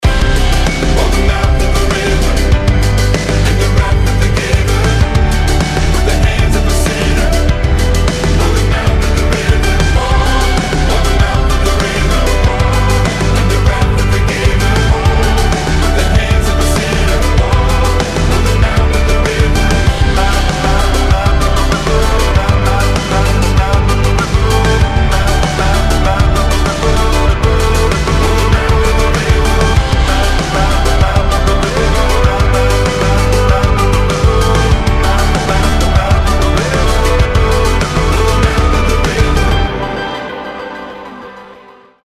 • Качество: 320, Stereo
мужской вокал
Alternative Rock